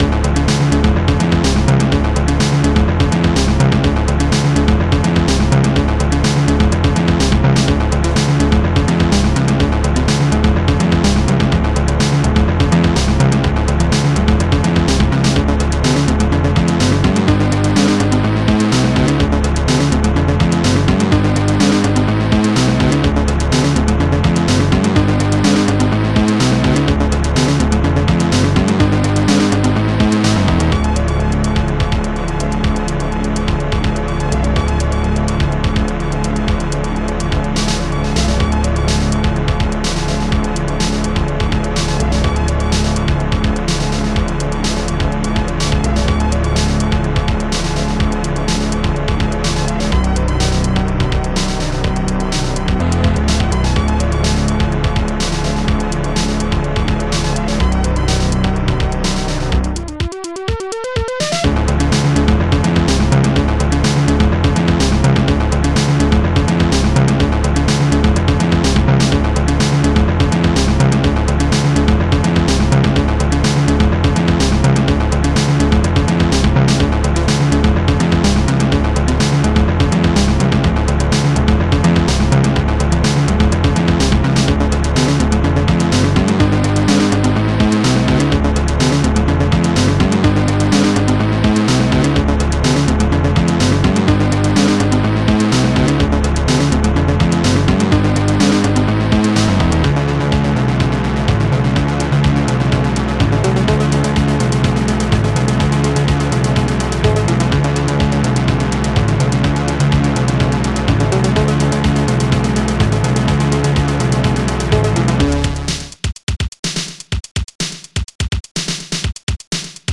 Protracker M.K.
ST-24:bass-modking
ST-24:bassdrumking
ST-24:snarejap
ST-24:hihatjap
ST-24:stringskingmin1
ST-24:lead-brassking